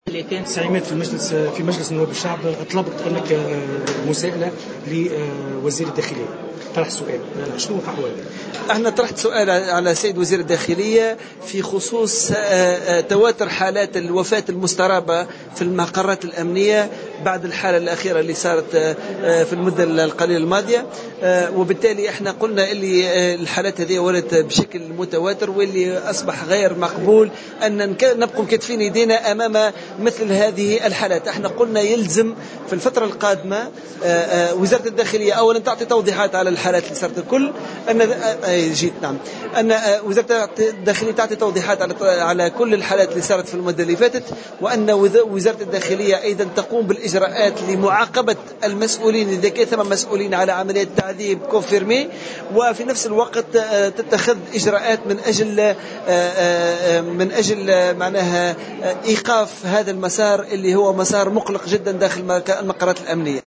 ودعا الدايمي وزير الداخلية إلى محاسبة المسؤولين المورطين في التعذيب وتقديم التوضيحات حول هذه الانتهاكات. وجاءت هذه التصريحات على هامش انعقاد اليوم المجلس الوطني لحزب المؤتمر من أجل الجمهورية والذي يتواصل إلى يوم غد.